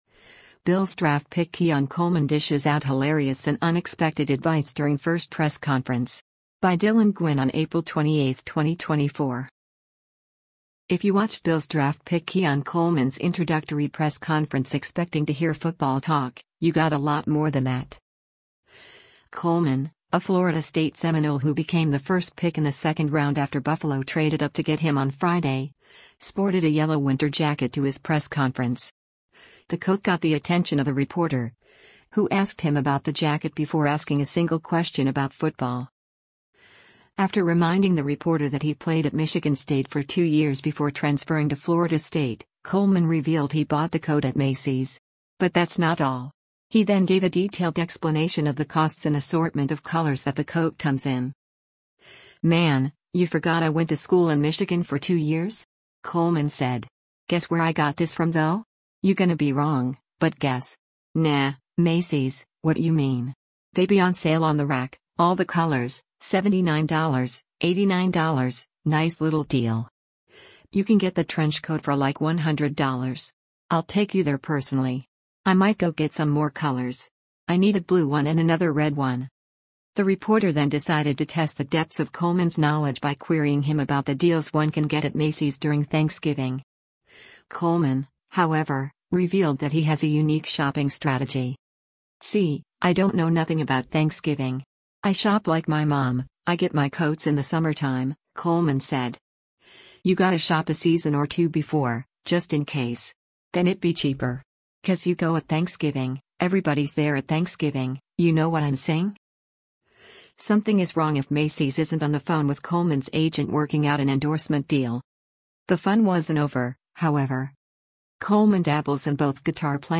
Bills Draft Pick Keon Coleman Dishes Out Hilarious and Unexpected Advice During First Press Conference
The coat got the attention of a reporter, who asked him about the jacket before asking a single question about football.